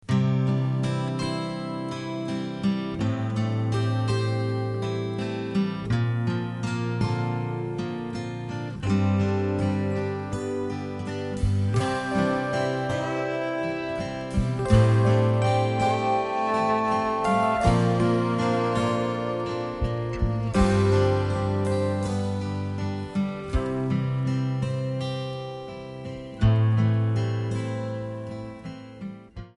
C/G
MPEG 1 Layer 3 (Stereo)
Backing track Karaoke
Country, Duets, 1990s